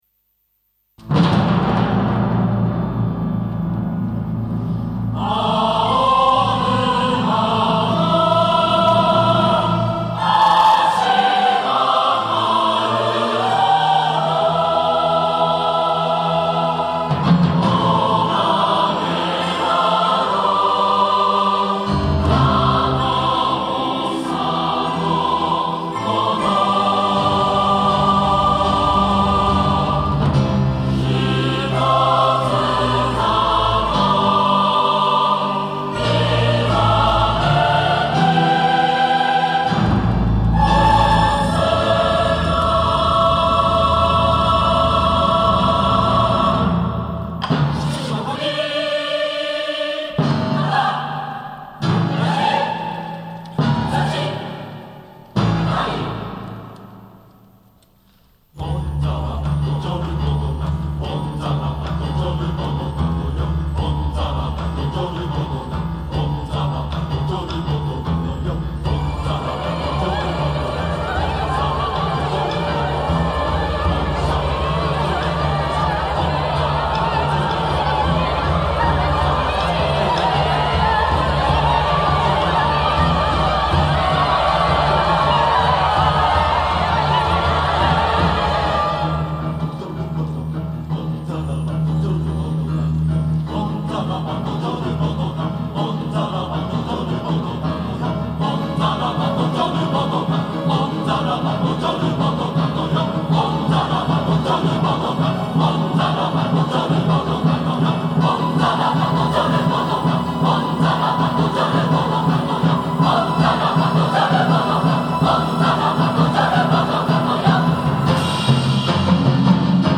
Chorus/Symphonic Works Reference CD